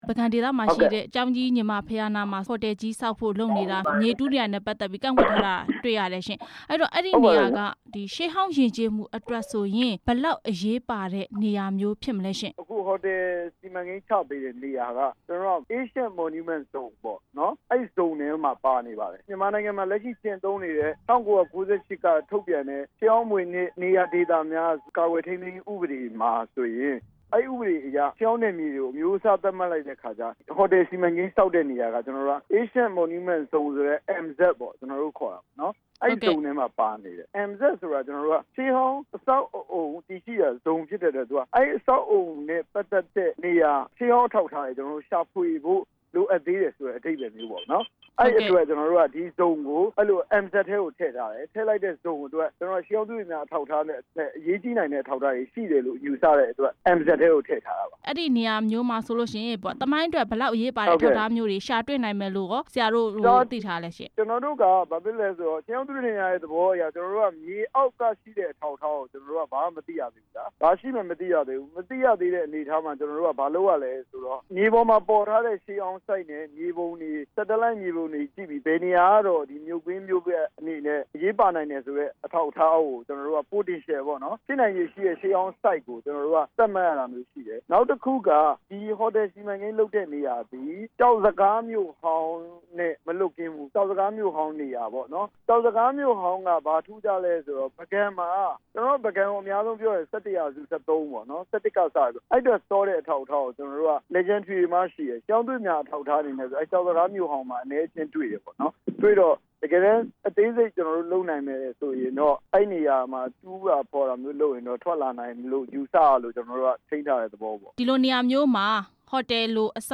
ပုဂံရှေးဟောင်းဇုန်ထဲမှာ ဟိုတယ်ဆောက်လုပ်နေမှု မေးမြန်းချက်